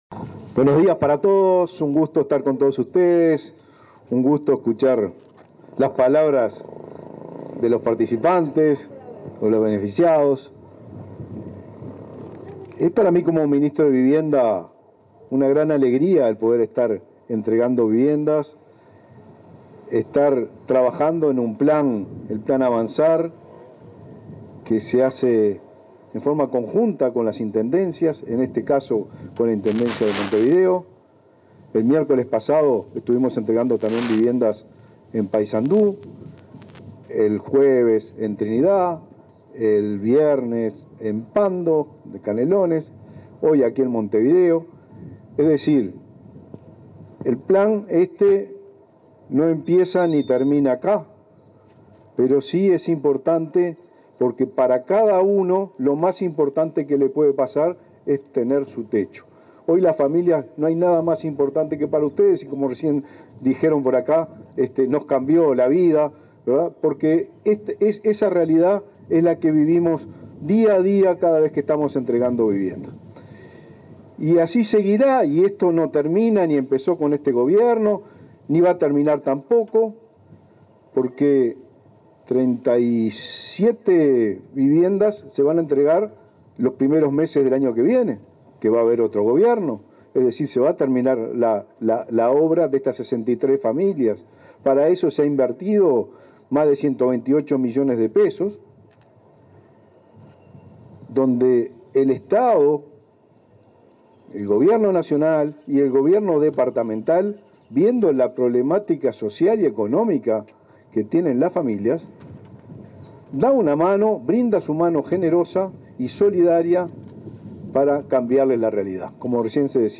Palabras del ministro de Vivienda, Raúl Lozano
El ministro de Vivienda y Ordenamiento Territorial, Raúl Lozano, participó en el acto de entrega de viviendas del plan Avanzar en Malvín Norte,